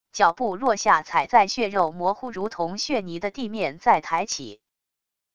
脚步落下踩在血肉模糊如同血泥的地面再抬起wav音频